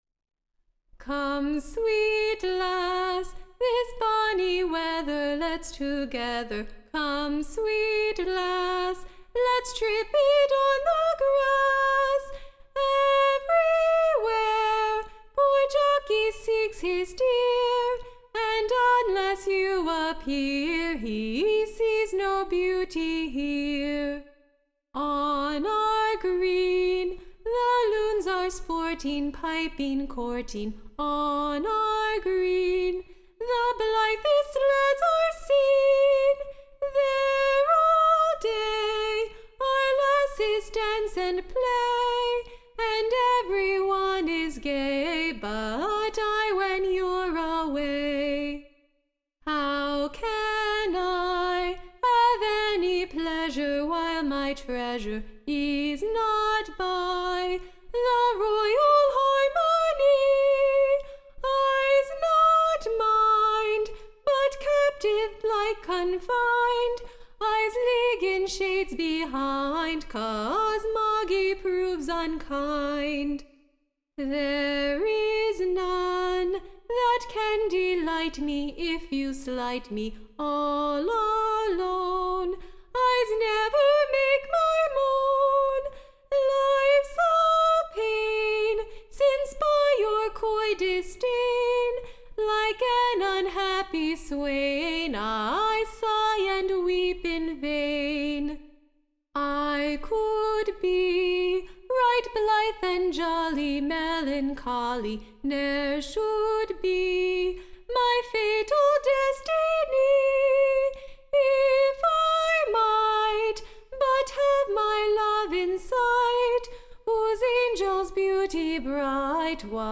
SCOTCH Song